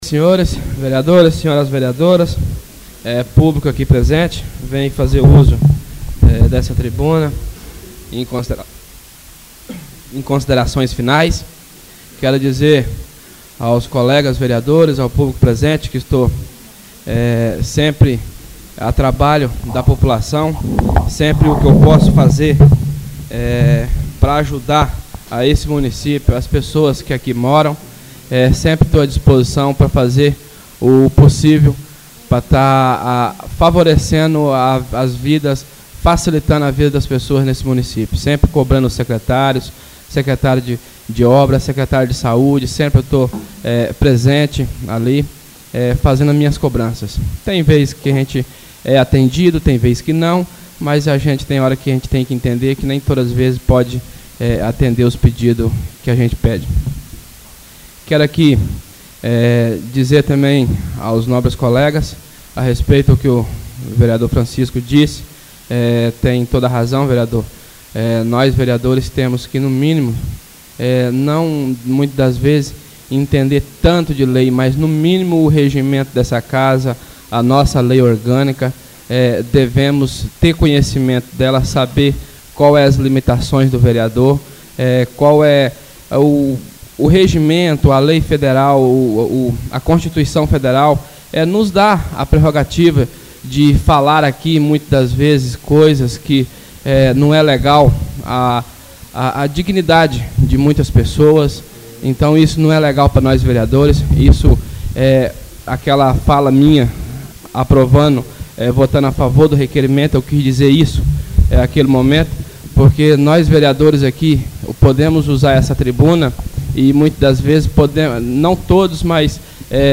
Oradores das Explicações Pessoais (21ª Ordinária da 3ª Sessão Legislativa da 6ª Legislatura)